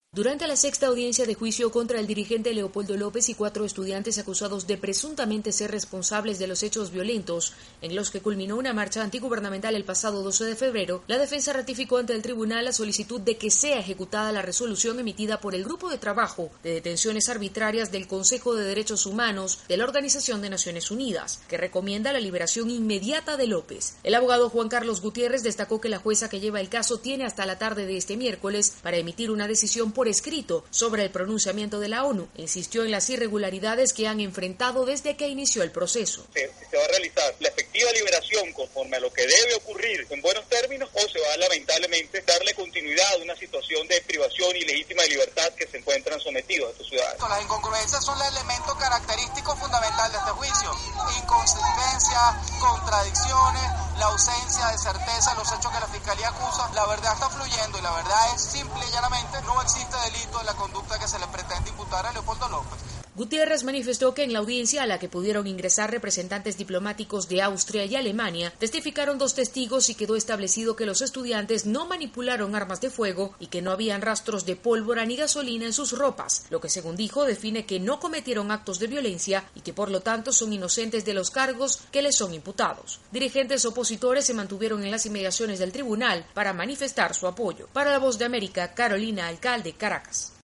Se espera que en las próximas horas un tribunal decida la resolución de la ONU que recomienda la liberación del líder opositor Leopoldo López, cuya próxima audiencia de juicio fue fijada para el 28 de octubre. Desde Caracas informa